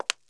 CONCRETE R4.WAV